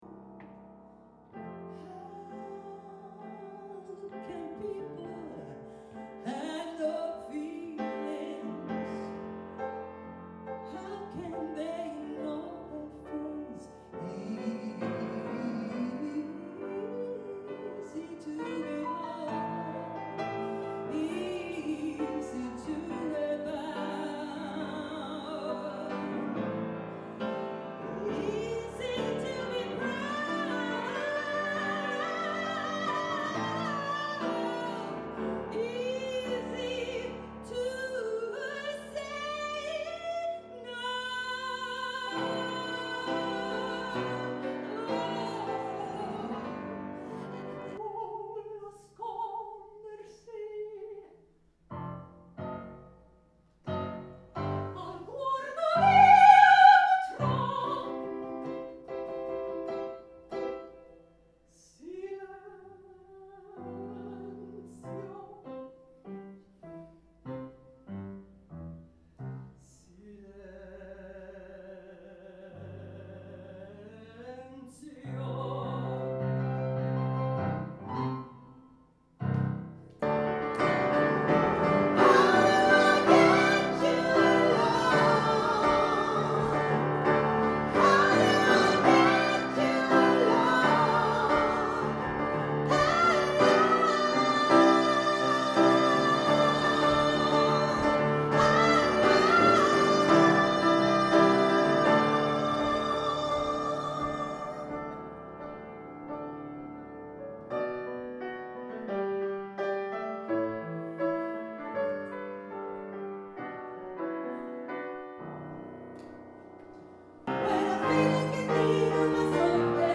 Här kan du höra några klipp på exempel på hur jag låter live när jag sjunger lite olika genre.